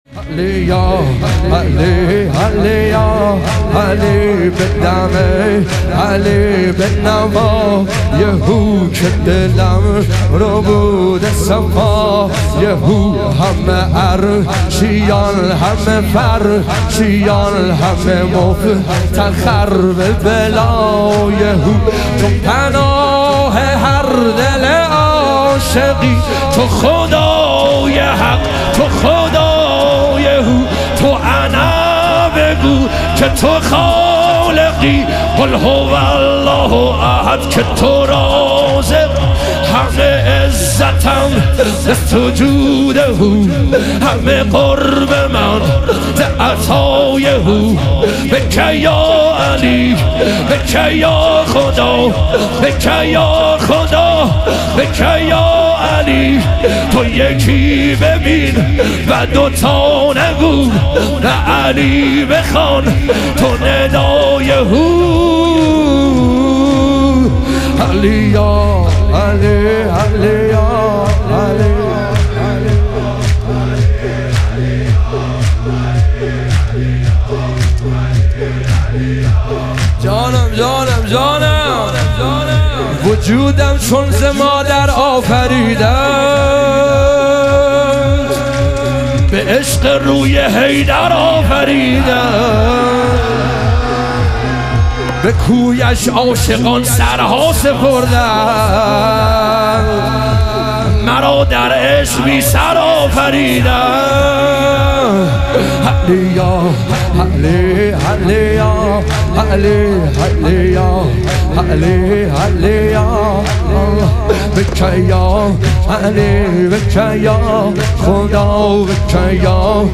لیالی قدر و شهادت امیرالمومنین علیه السلام - تک